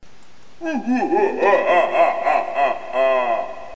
Sound Effect - Evil Laugh
Category ⚡ Sound Effects